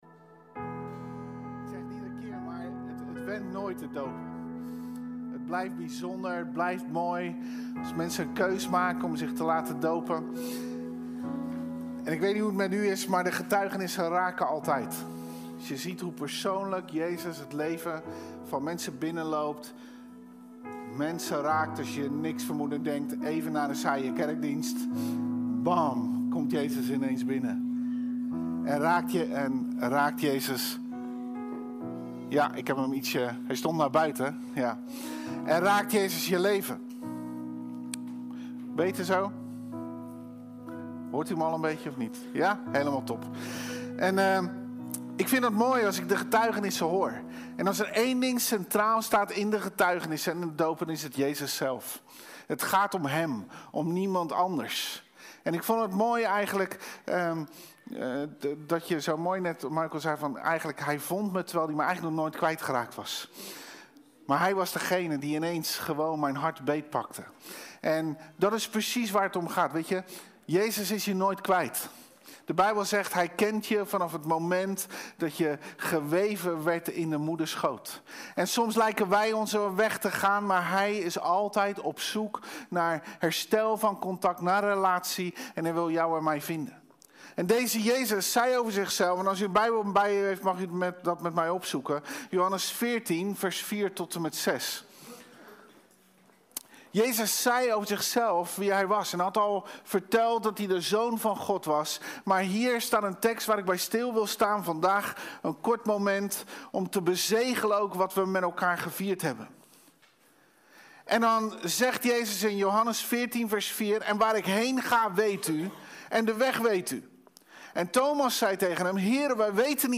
Vandaag was het feest: er zijn 5 mensen gedoopt!